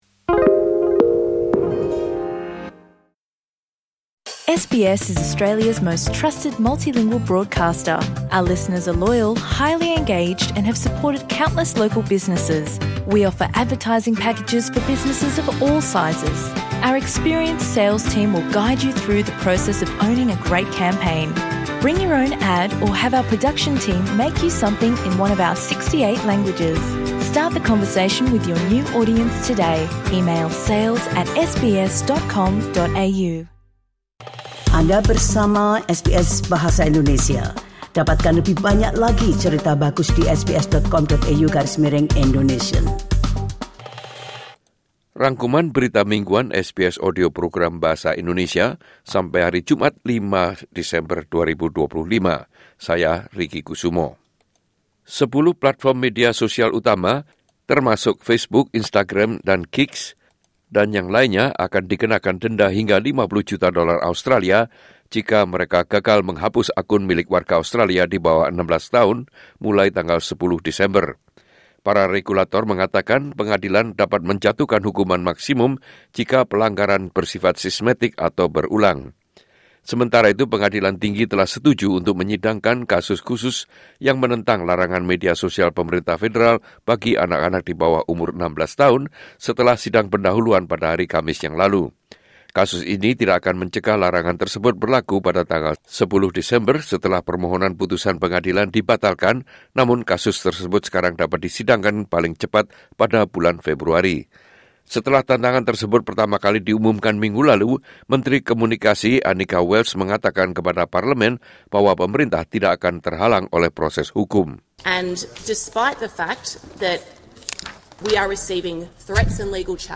Rangkuman Berita Mingguan SBS Audio Program Bahasa Indonesia - Jumat 5 Desember 2025